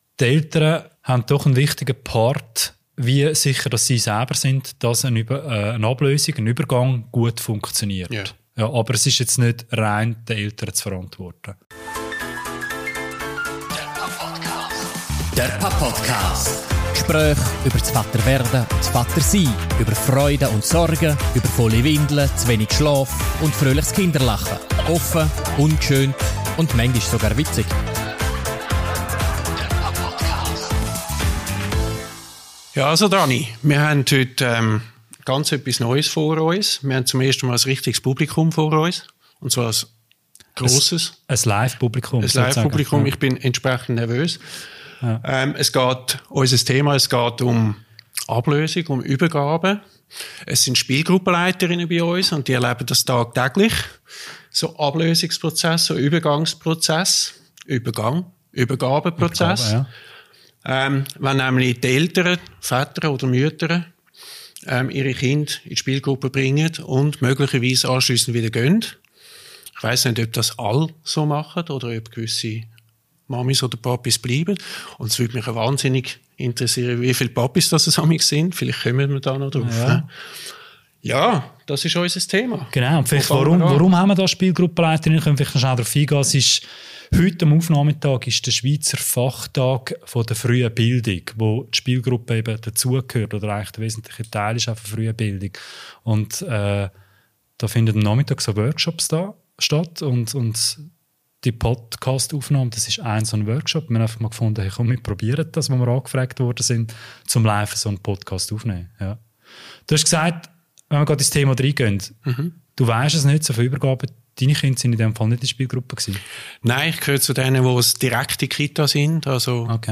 Das Gespräch war Teil eines Workshops an der Fachtagung Frühe Bildung des Schweizerischen Spielgruppen-LeiterInnen-Verbands (SSLV) - und fand live vor und mit Publikum statt - eine Premiere für den PAPodcast.